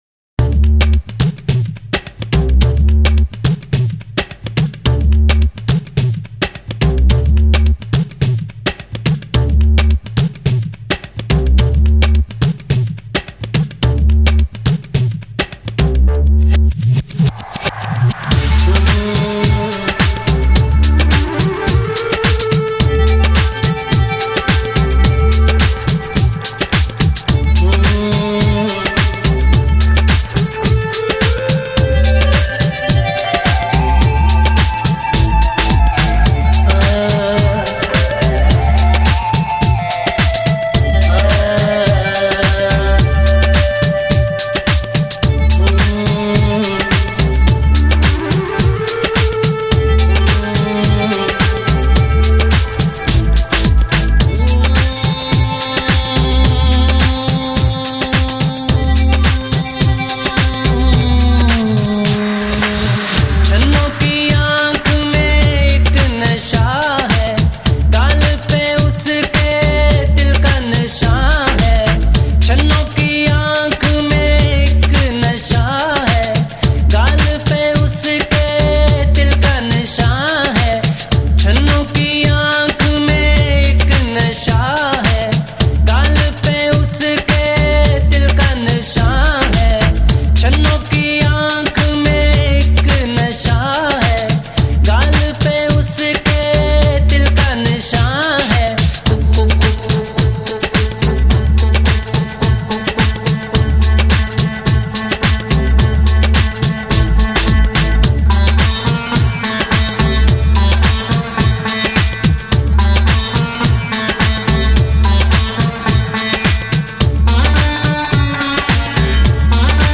Chill Out, Lounge, Downtempo Носитель